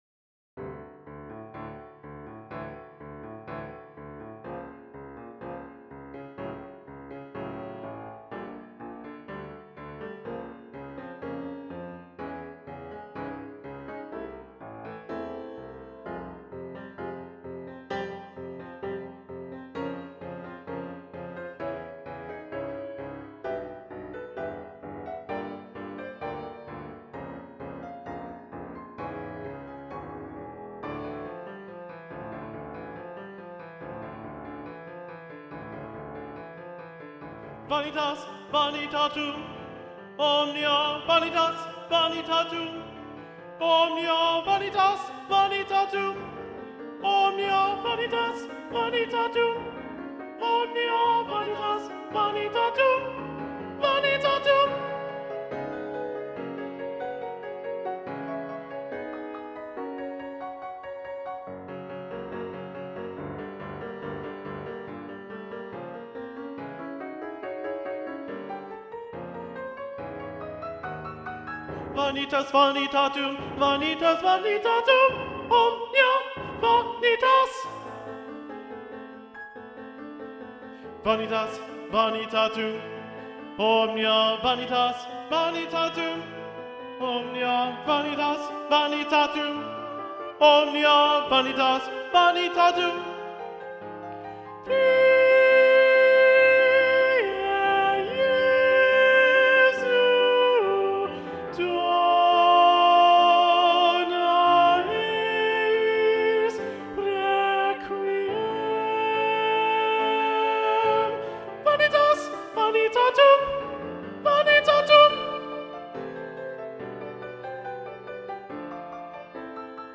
Video Only: Vanitas Vanitatum - Alto 2 PRedominant